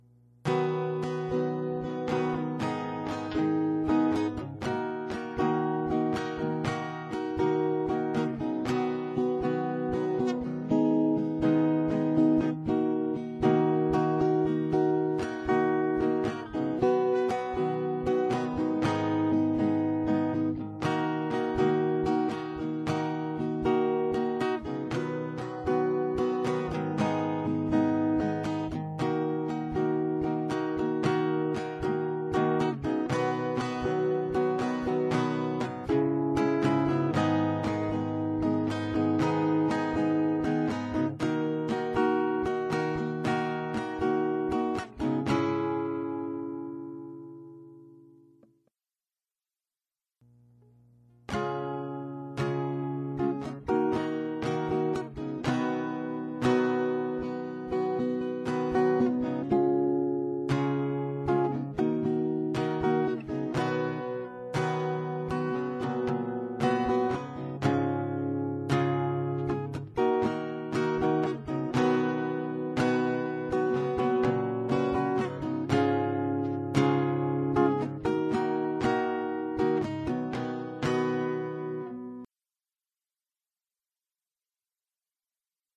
to figure out how to play this on guitar